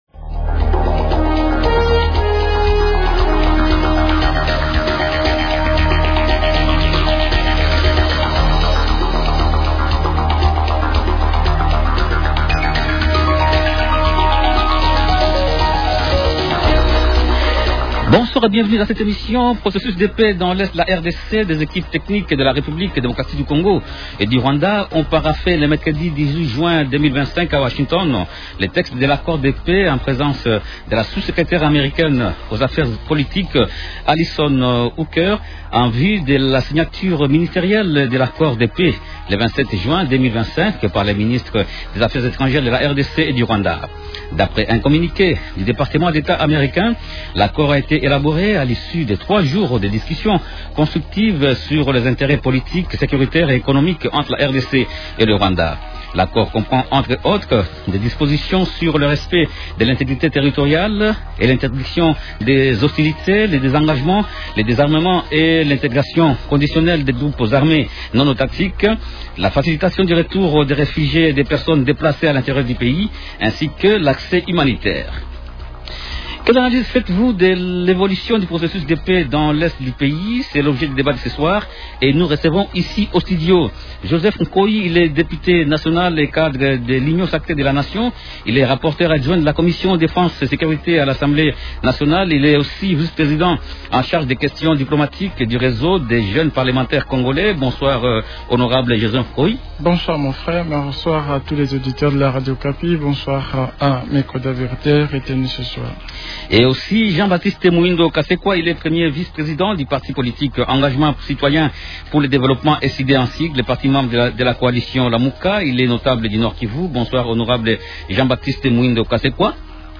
Invités : -Joseph Nkoy, député national et cadre de l’Union sacrée de la nation.